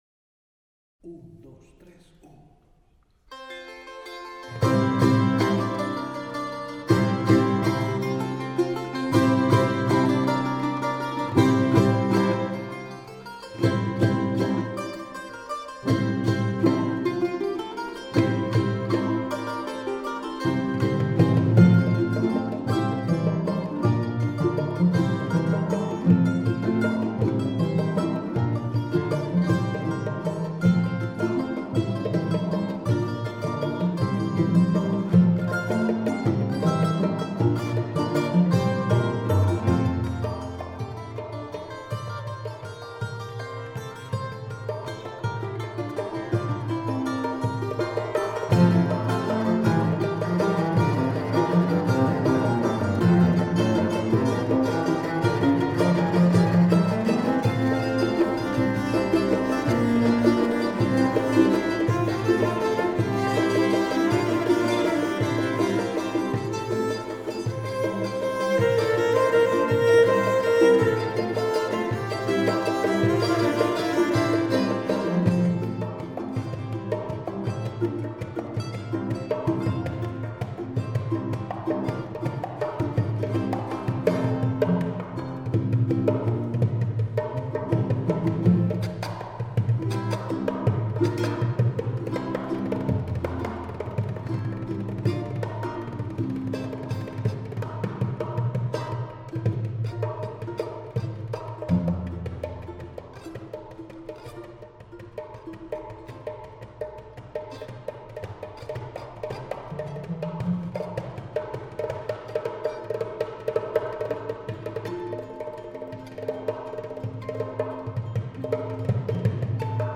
比同时期欧洲音乐更欢快，更富民间韵味，大家可以听到西特琴、基塔隆尼琴和非常丰富的打击乐器。